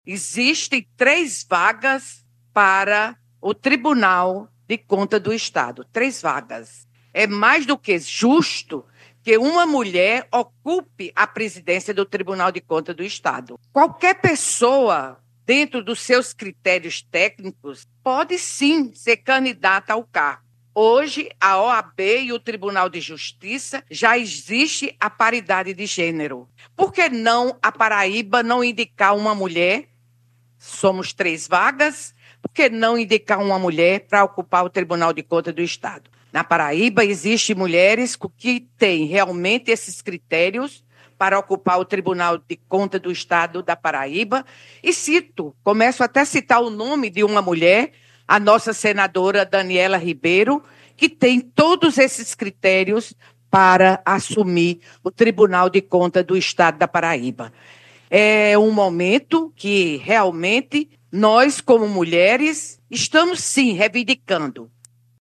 “É mais do que justo que uma mulher ocupe a presidência do Tribunal de Contas do Estado. Qualquer pessoa dentro dos seus critérios técnicos pode sim ser candidato ao cargo. Hoje, a OAB e o TJ já existe a paridade de gênero. Por que não a Paraíba indica uma mulher?! São três vagas! Na Paraíba existem mulheres que realmente têm esses critérios para ocupar o TCE e cito o nome de Daniella Ribeiro, que tem todos esses critérios. É o momento que realmente nós como mulheres estamos sim reivindicando”, destacou em entrevista ao programa Correio Verdade, da Rádio Correio 98 FM desta terça-feira (10/12).